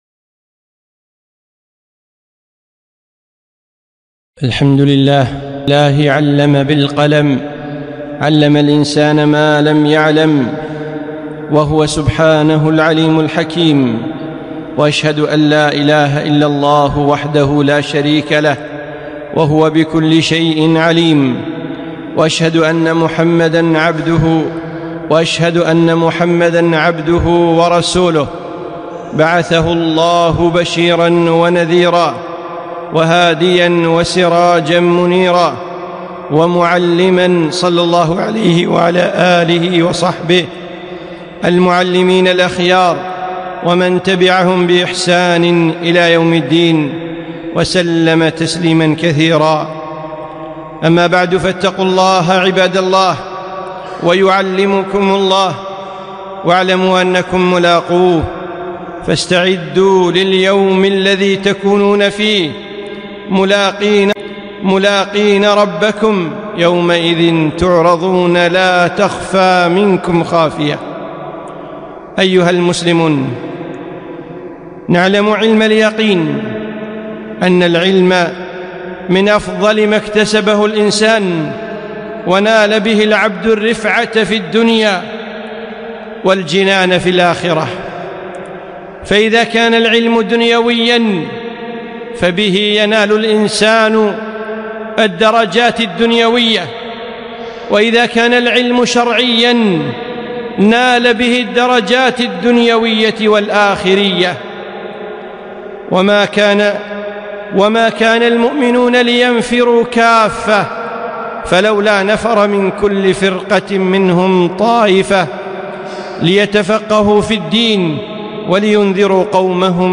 خطبة - فضل العلم والعلماء